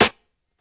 Boom-Bap Snare 57.wav